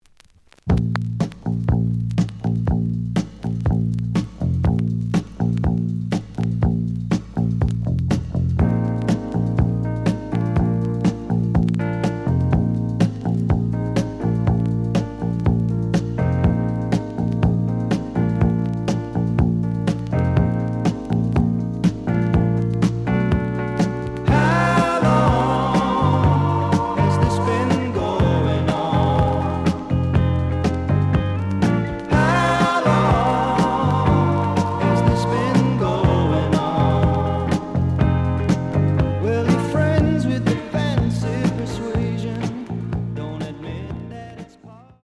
試聴は実際のレコードから録音しています。
●Genre: Rock / Pop
●Record Grading: VG (両面のラベルにダメージ。A面のラベルに書き込み。)